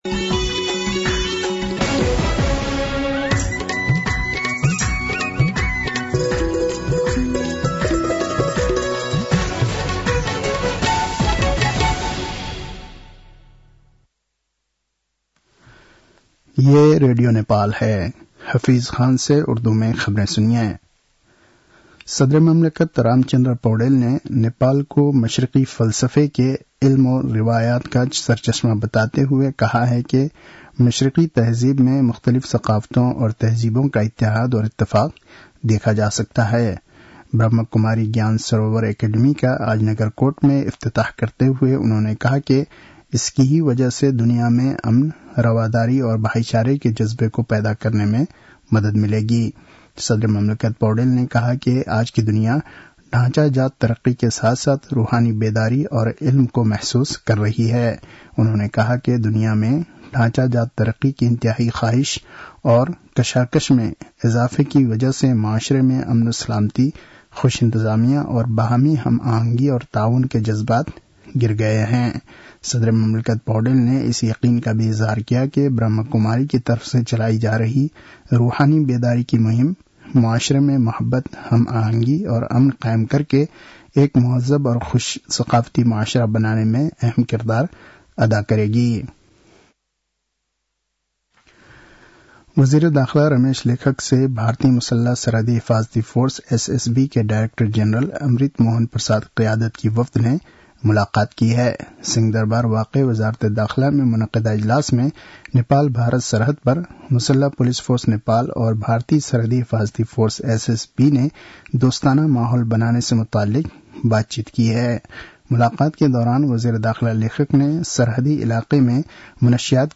उर्दु भाषामा समाचार : ३ मंसिर , २०८१
Urdu-News-8-2.mp3